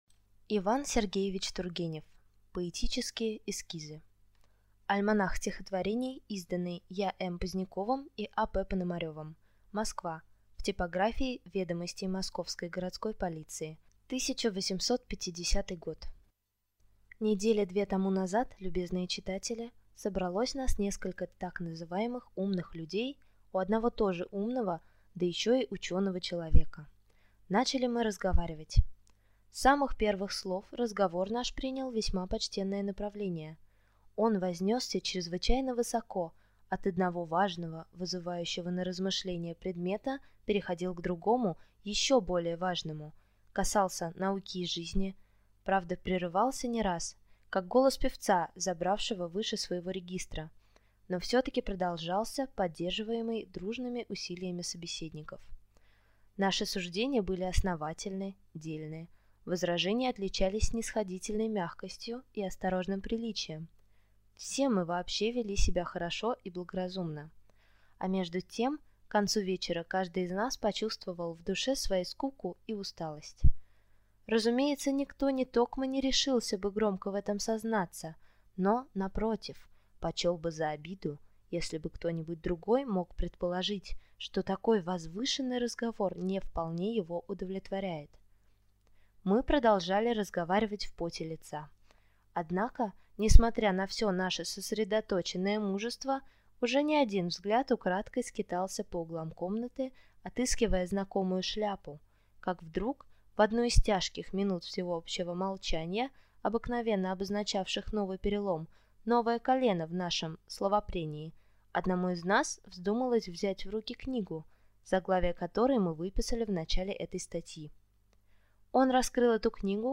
Аудиокнига Поэтические эскизы | Библиотека аудиокниг